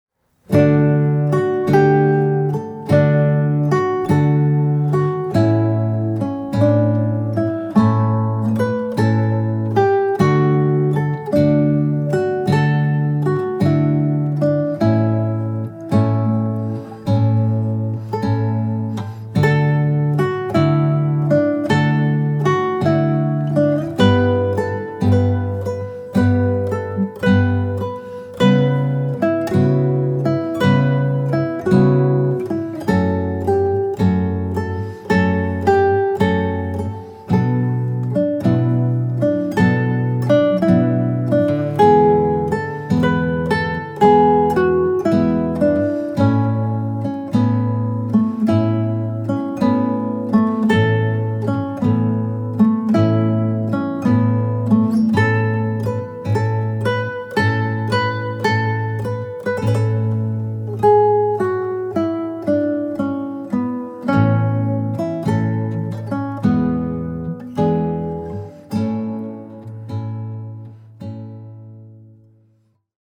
Largo Konzert f Gitarre in D Dur (A Vivaldi)mpga1,67 MB